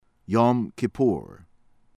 YOADIMNADJI, PASCAL pahs-KAHL   yoo-ah-DEEM-nah-jee